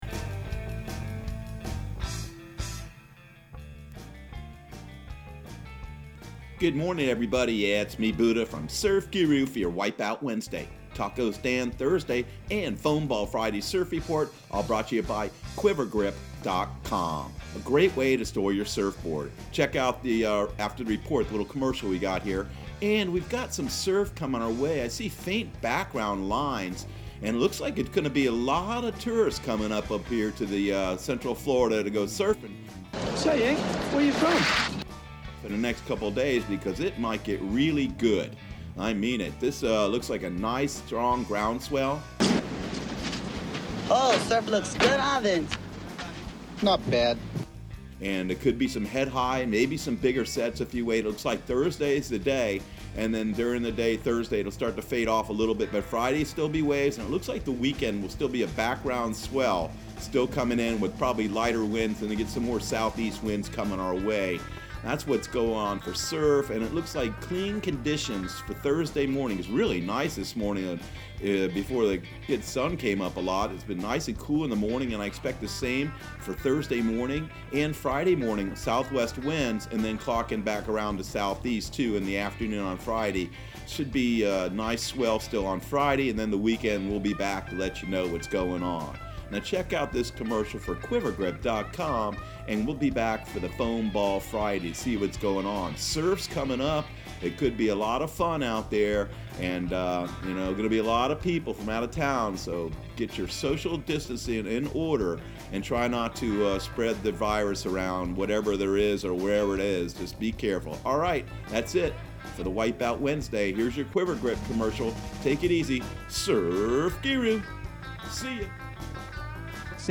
Surf Guru Surf Report and Forecast 05/20/2020 Audio surf report and surf forecast on May 20 for Central Florida and the Southeast.